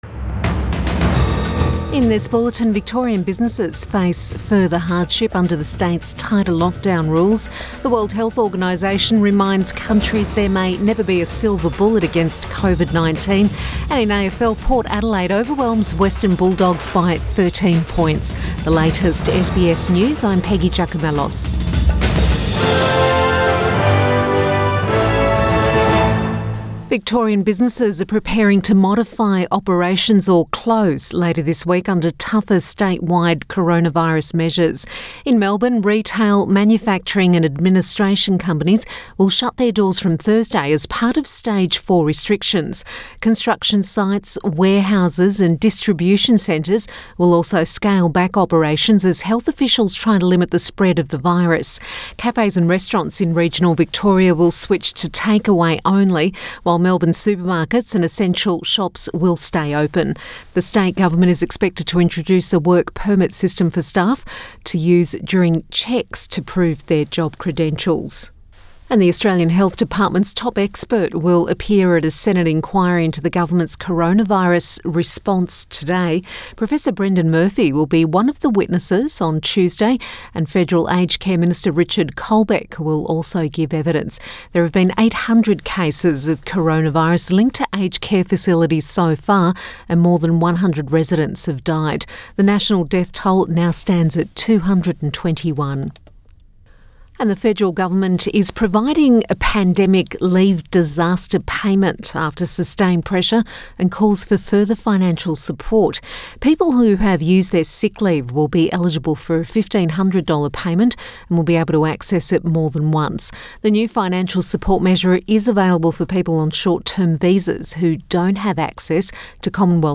AM Bulletin 4 August 2020